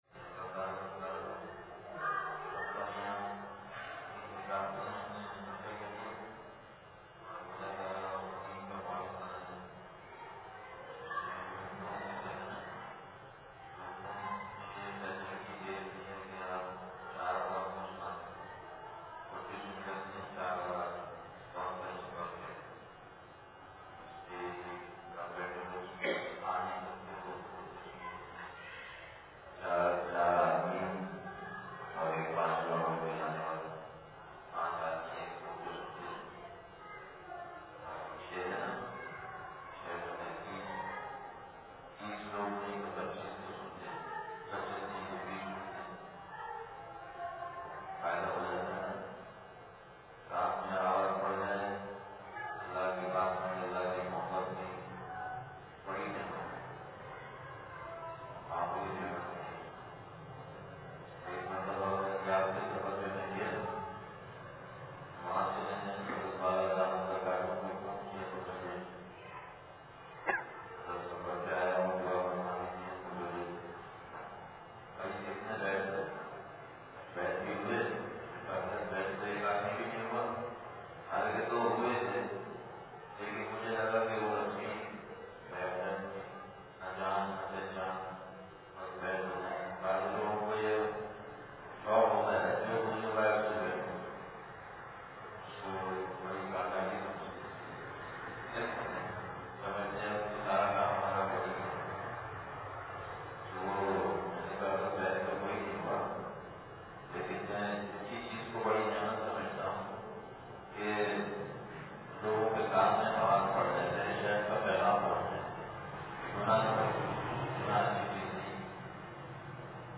بیان بعد نماز فجر مسجد صدیقِ اکبر دنیا پور شہر